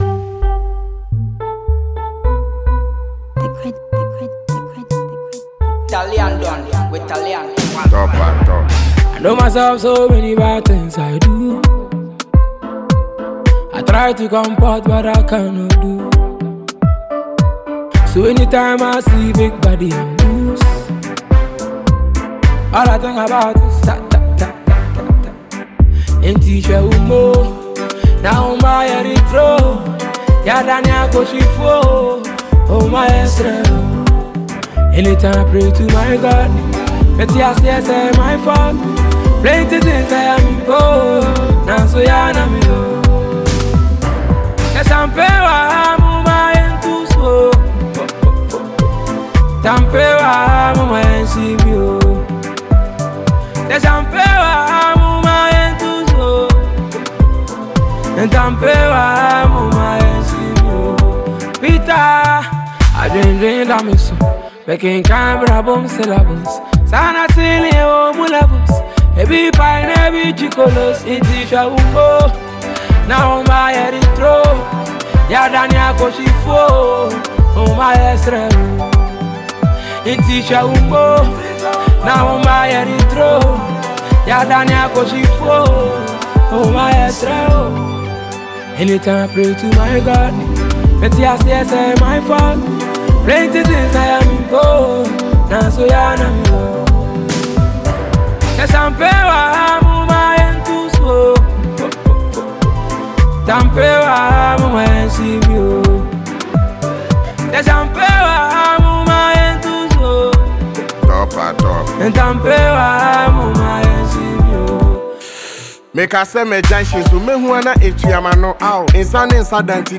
award-winning Ghanaian rapper
modern highlife and Afrobeats